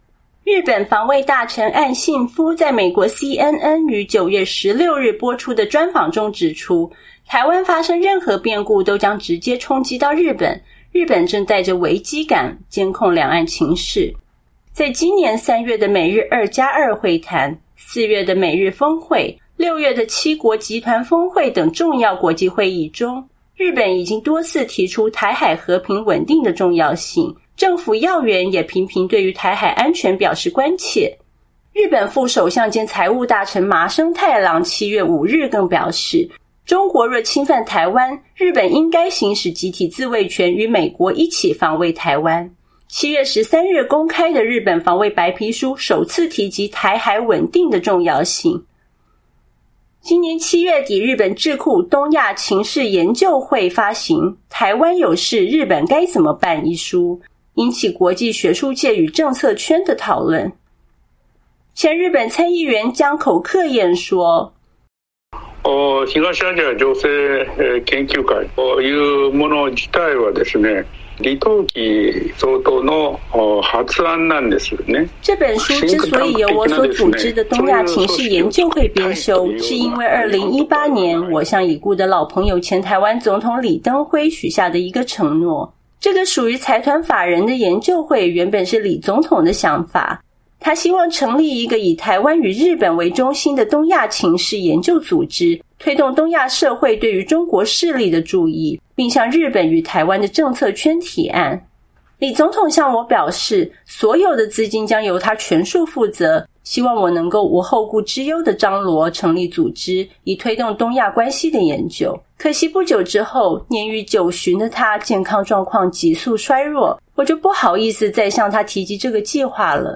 VOA專訪前日本參議員江口克彥：臺灣有事等於日美有事